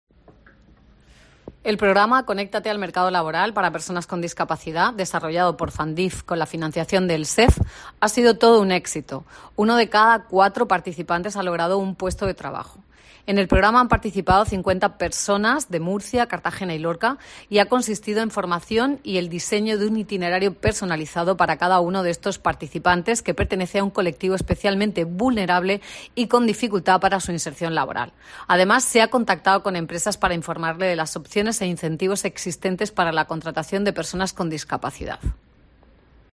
Marisa López, directora del SEF